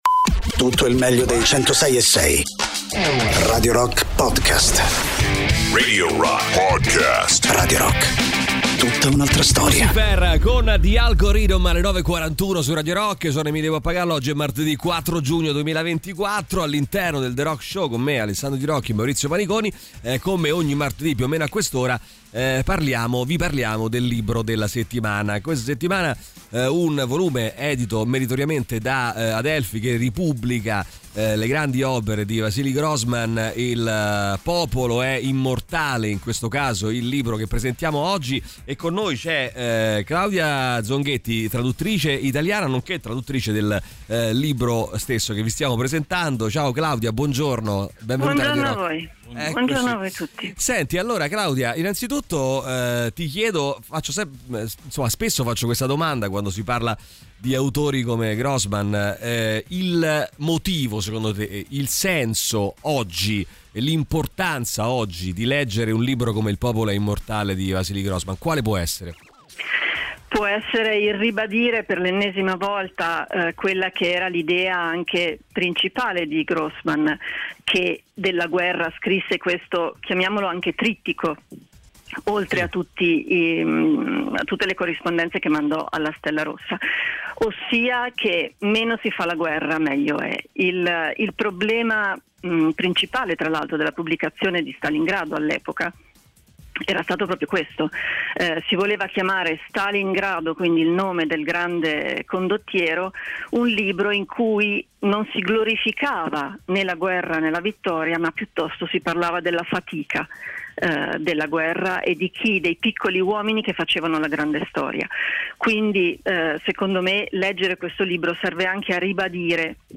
Interviste
in collegamento telefonico
durante il ‘The Rock Show’, sui 106.6 di Radio Rock